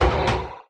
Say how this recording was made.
should be correct audio levels.